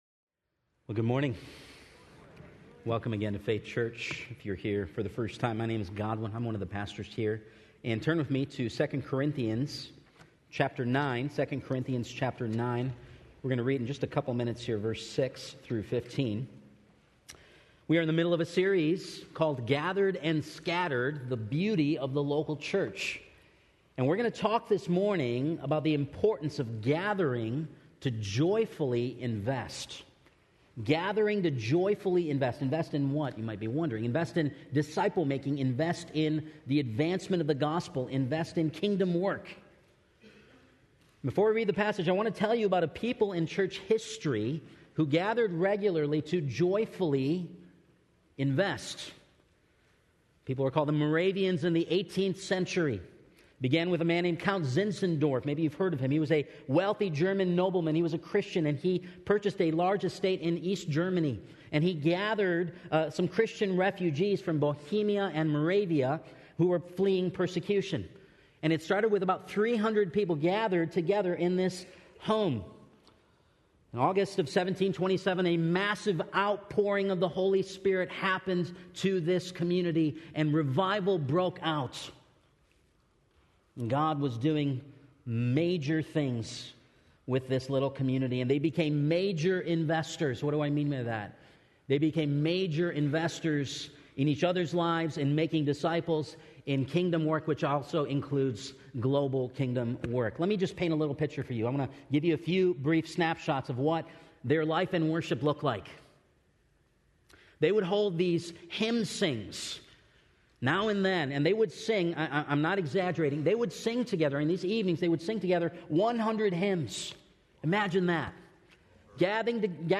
Faith Church Sermon Podcast Gathered and Scattered - Gather to Joyfully Invest Jun 03 2019 | 00:36:22 1x 00:00 / 00:36:22 Subscribe Share Spotify RSS Feed Share Link Embed